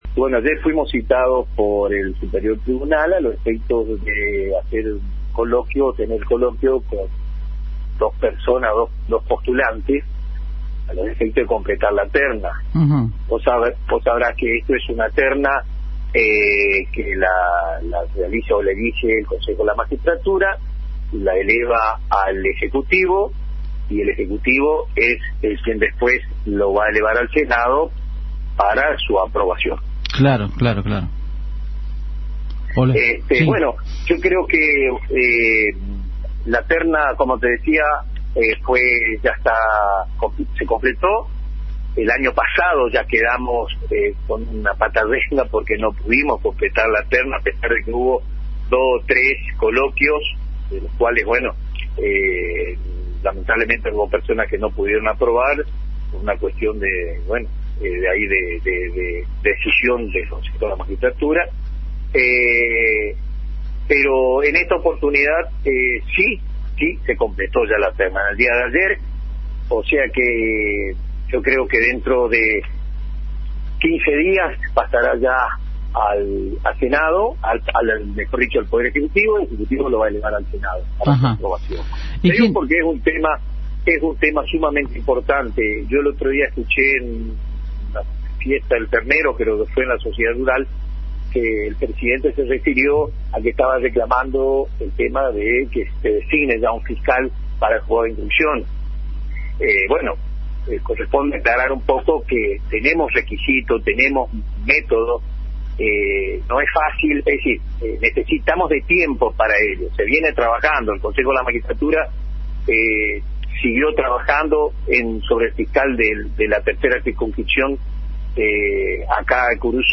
"La terna ya se completó. El año pasado habíamos quedado con una pata renga, habían personas que no pudieron aprobar y en ésta oportunidad se completó la terna, así que dentro de 15 días pasará al Ejecutivo y desde allí pasará al Senado para su aprobación", explicó el letrado en declaraciones a Agenda 970 a través de la AM970 Radio Guarani.